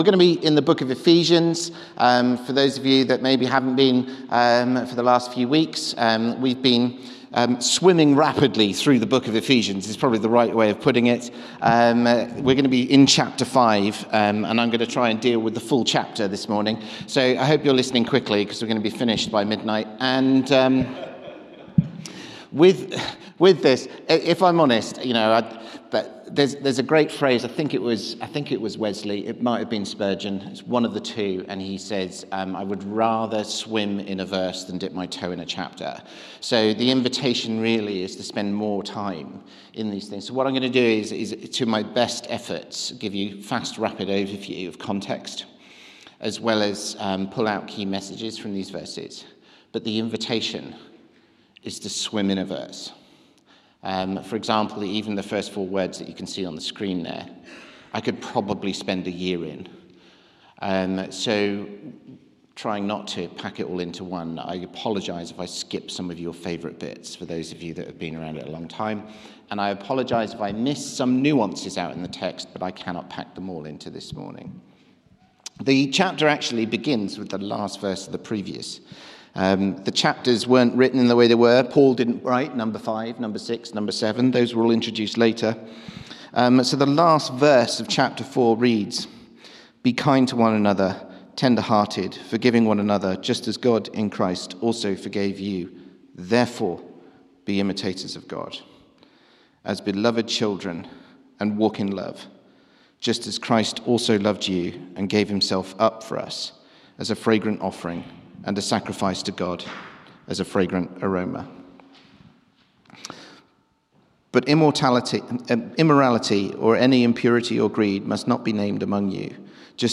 Sermon - Ephesians 5